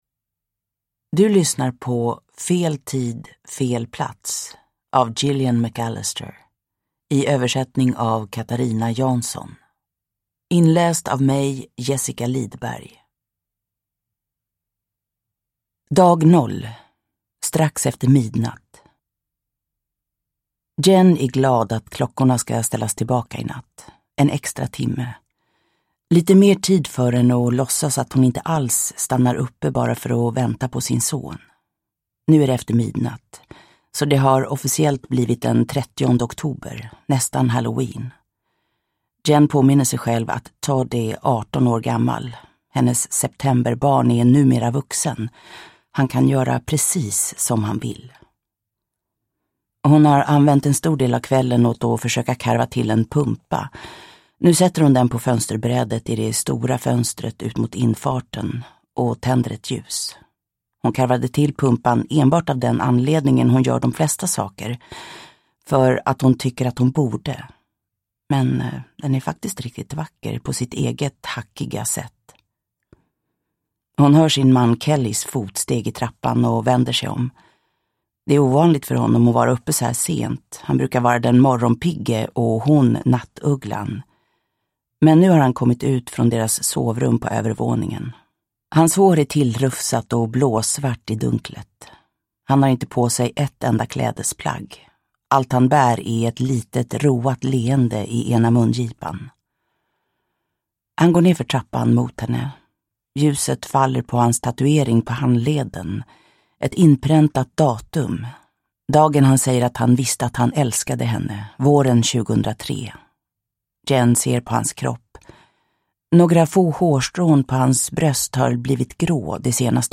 Fel tid, fel plats – Ljudbok – Laddas ner